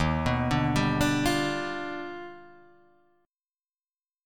D#+M9 chord